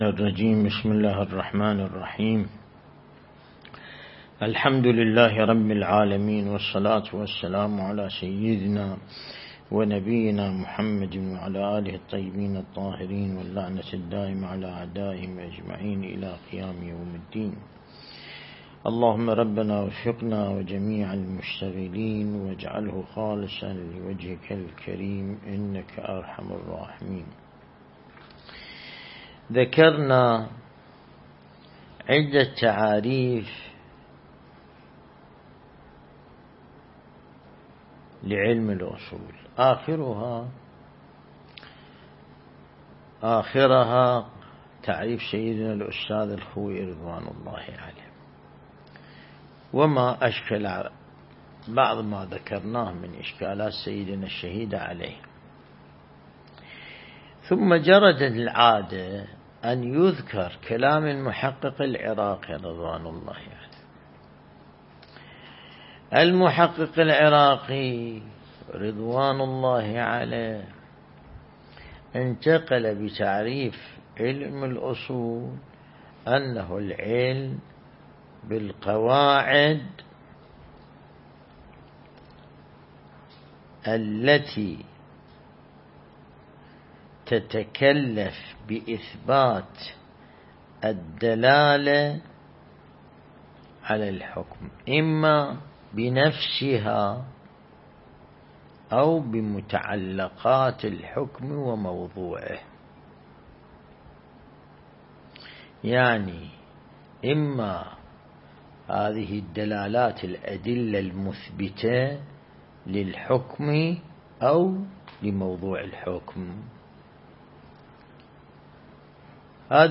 النجف الأشرف
دروس بحث خارج اصول